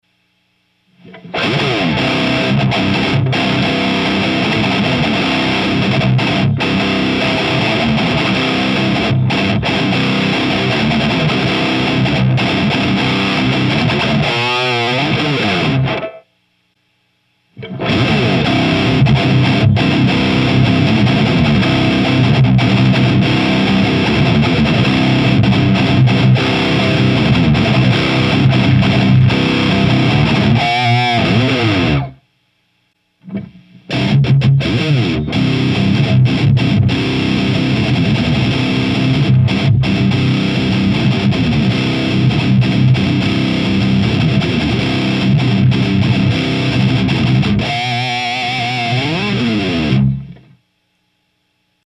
以前の歪み。
DSLはディープ、トーンシフト、ってスイッチがあるのでその差も比較してみてください。
LEED2→ディープオン（低音域強調）→トーンシフト（中音域をカットでドンシャリ）